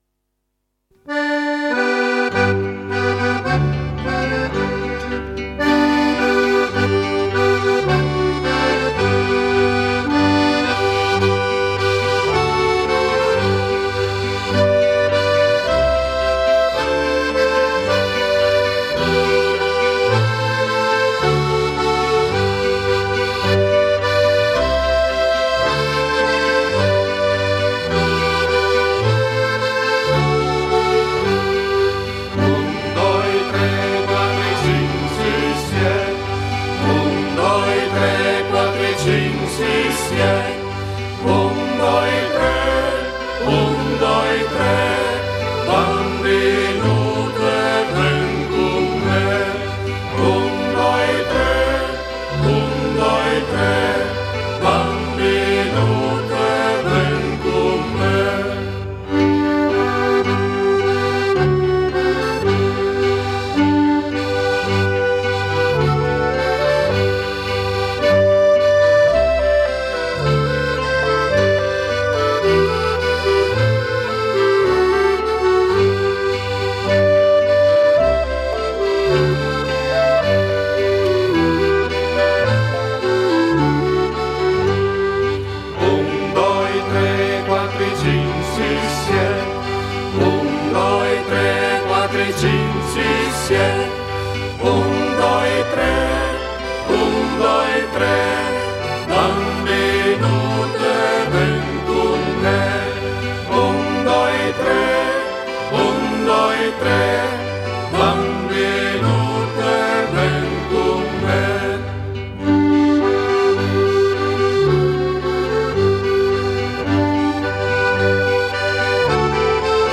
Ti presentiamo una tra le più diffuse in Friuli e non solo, eseguita dall’orchestra del gruppo Folcloristico Pasian di Prato: “I Siet Pas”. I Siet Pas è una canzone a ballo che un tempo veniva utilizzata per insegnare ai bambini a contare fino a sette, molto conosciuta in una vasta area che comprende Friuli, Istria, Austria, e Baviera.
Qui di seguito è presente una bella registrazione della danza e del canto.
La coreografia è composta da 3 movimenti che si ripetono due volte (la seconda volta è cantata).